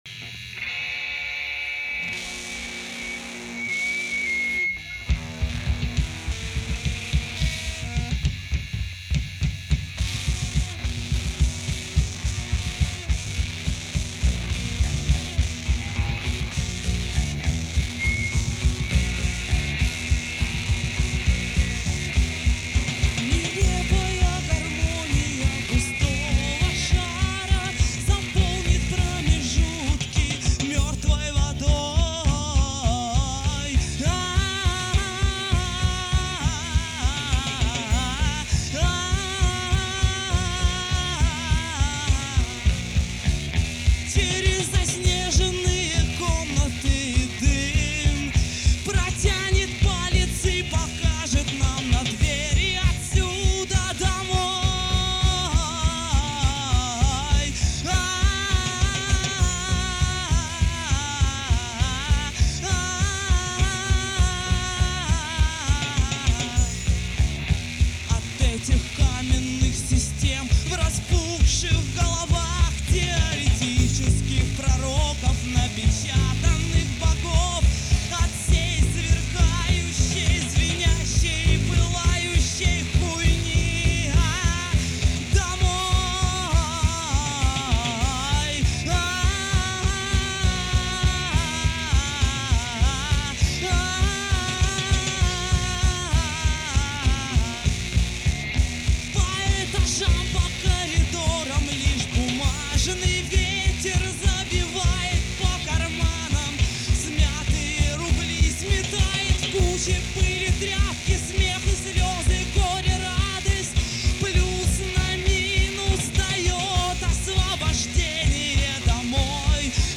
советская поэтесса, рок-певица и автор-исполнитель.
(Live)